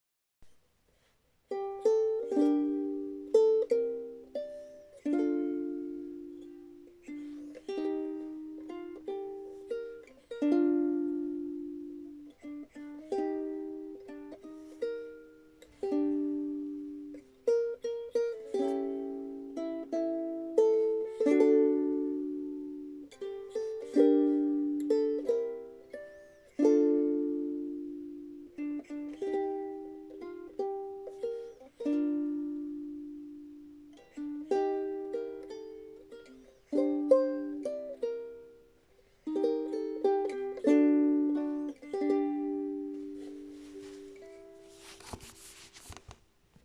日本の木ウクレレ　カヤ・ケヤキ・カエデ　コンサートロングネック №89
バランスよく心地よい鳴りの一本となりました。